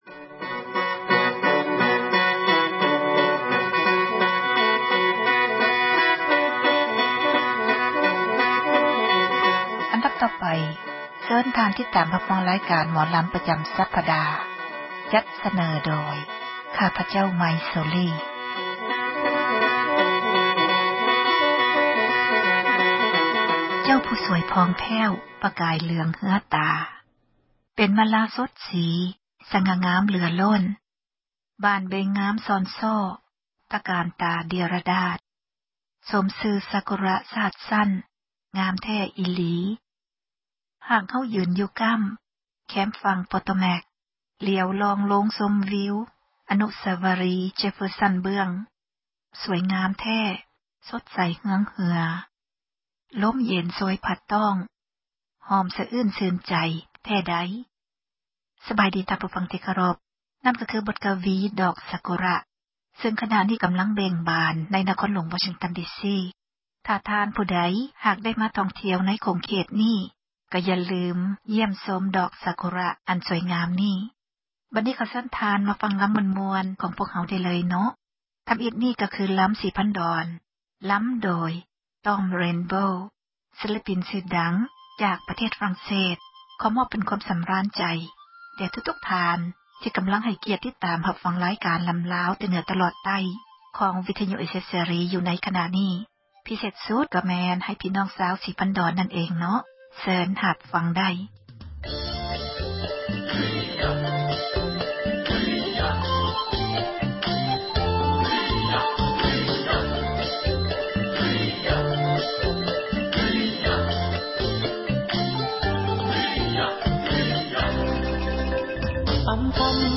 ຣາຍການ ໝໍລຳລາວ ປະຈຳ ສັປດາ ຈັດສເນີ ທ່ານ ໂດຍ